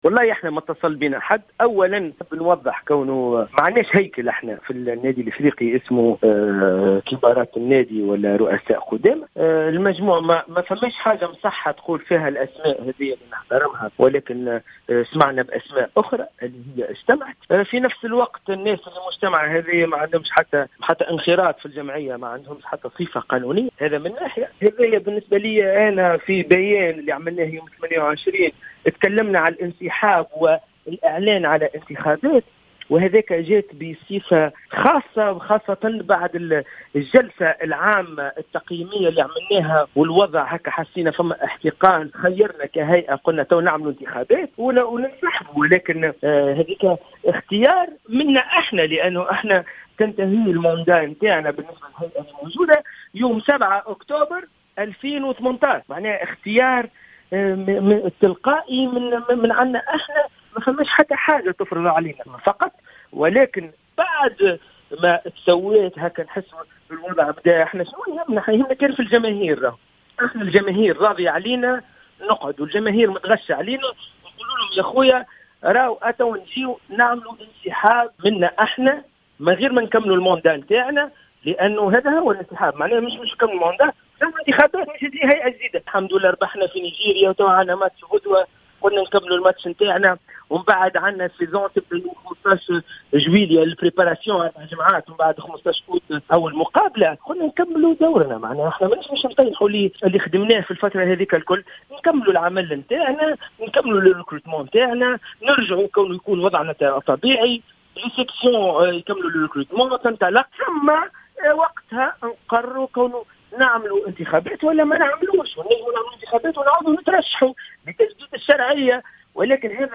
اكد رئيس النادي الافريقي سليم الرياحي في تصريح لجوهرة اف ام اليوم الخميس ان شرعية الهيئة الحالية تنتهي في اكتوبر 2018 و لا وجود لهيكل يسمى بقدماء النادي ليتم من خلاله إحداث لجنة تسييرية مؤقّتة .